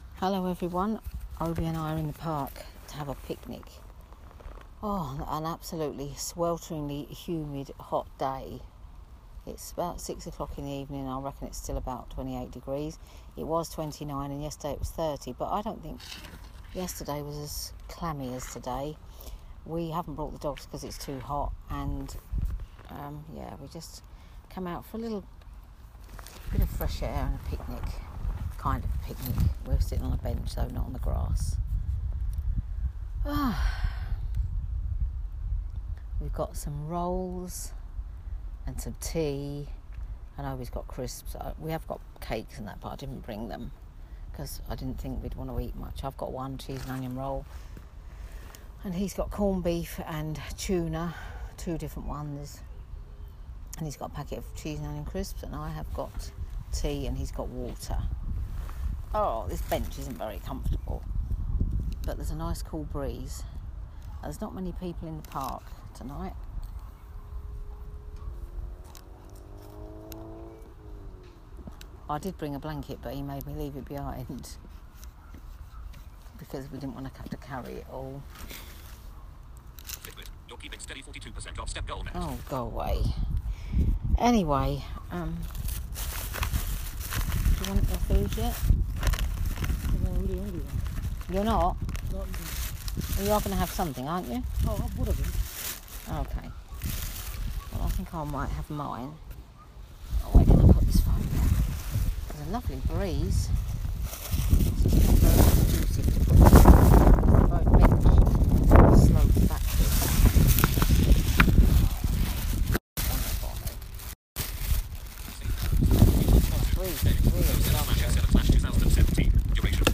Picnic in the park, Friday 7 July 2017
Please excuse the wind noise at the beginning and also that the recording kept cutting out for some reason.